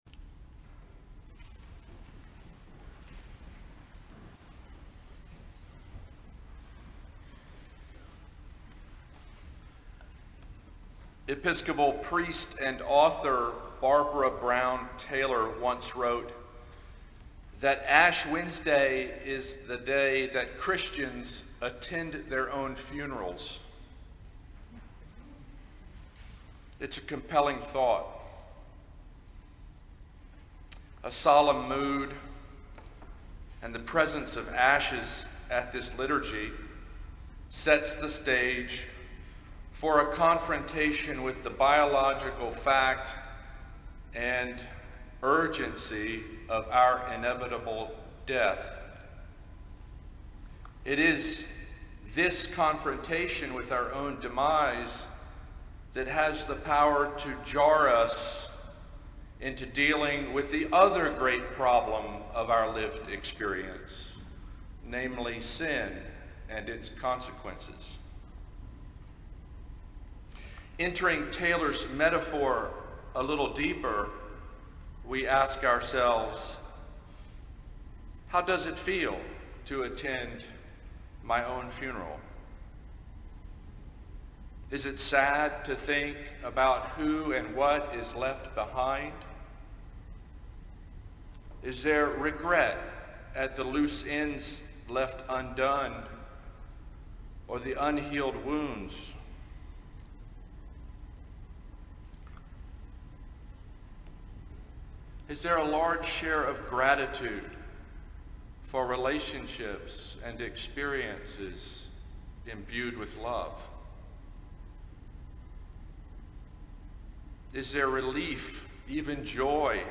Save Audio In tonight’s sermon